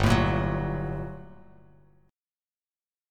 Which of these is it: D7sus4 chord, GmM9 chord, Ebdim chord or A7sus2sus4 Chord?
GmM9 chord